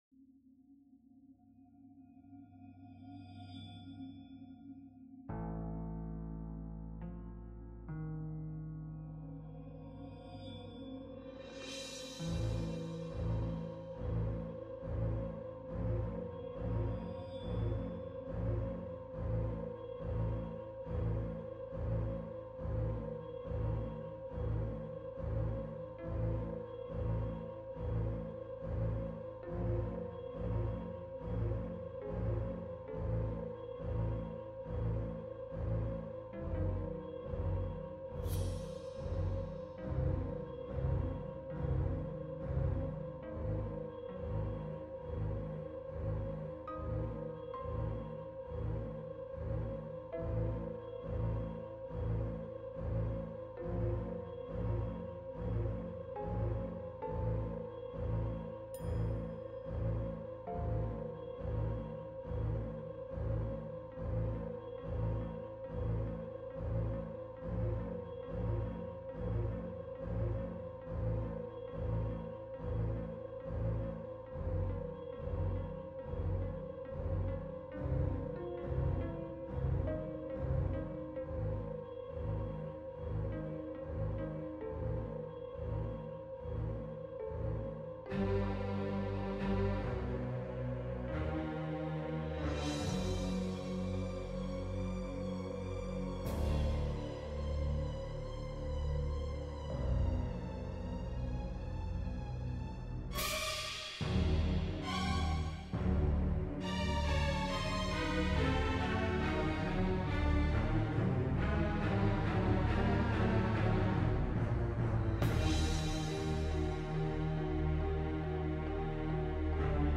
LAEoguwpt2v_Musique-Angoissante-1.mp3